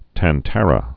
(tăn-tărə, -tärə)